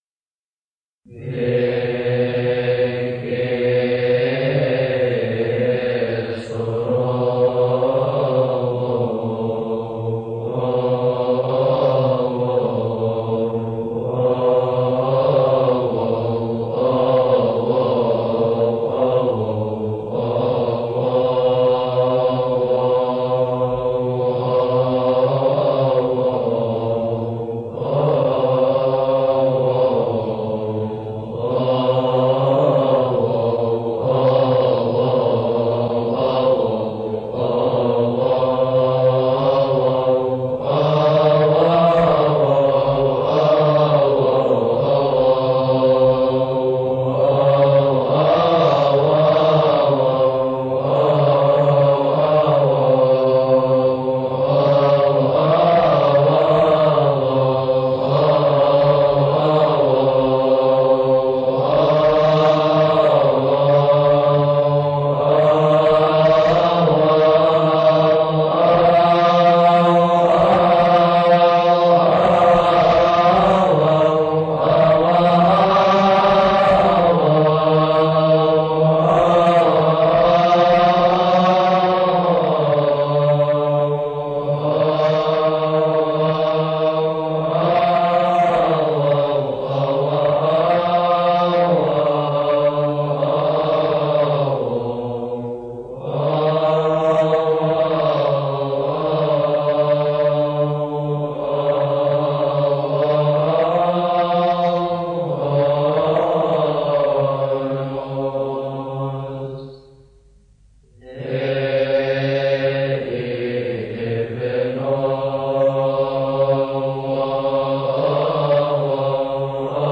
لحن بيك إثرونوس.mp3